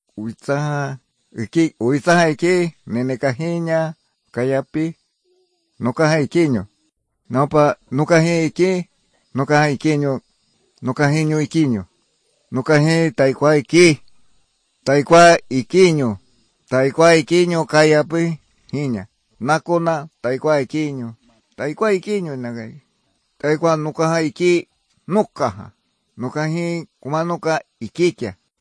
30 March 2015 at 1:46 pm The consonant inventory is small, but þ and ñ don’t fit with Polynesian, and neither do the nasal vowels.